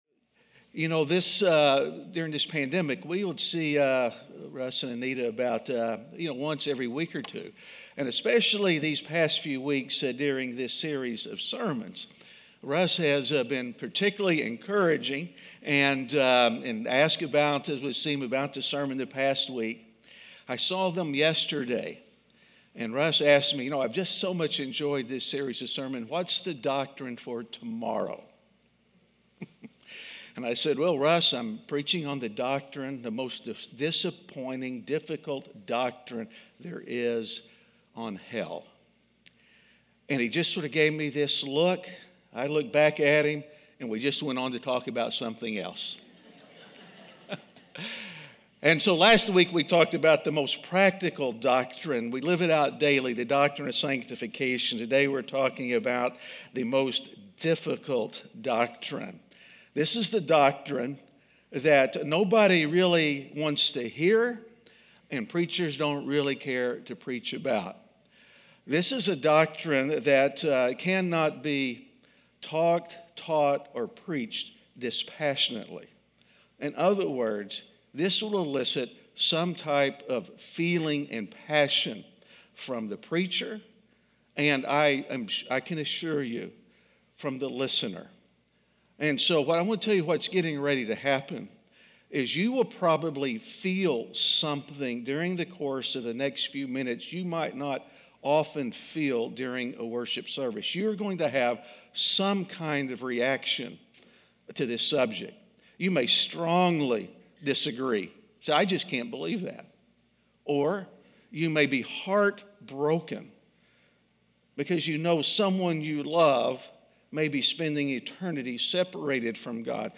Is There A Doctrine In The House? (Week 10) - Sermon.mp3